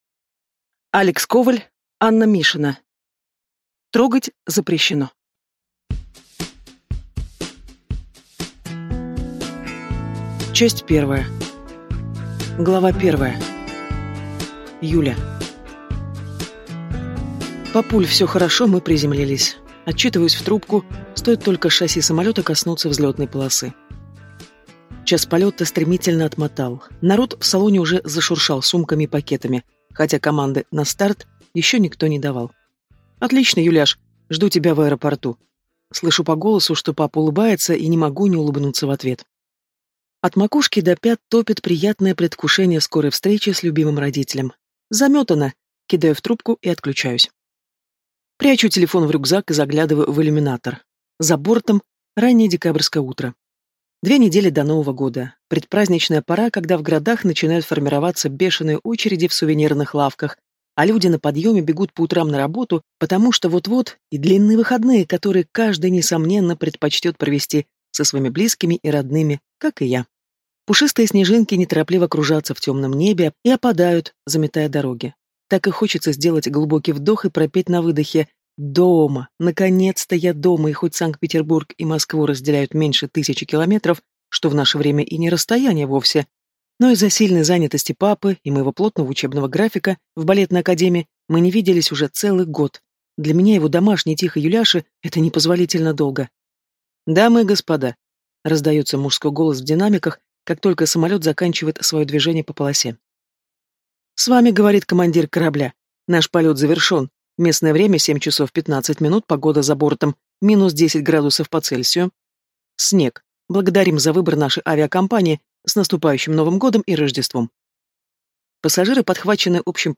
Аудиокнига Трогать запрещено | Библиотека аудиокниг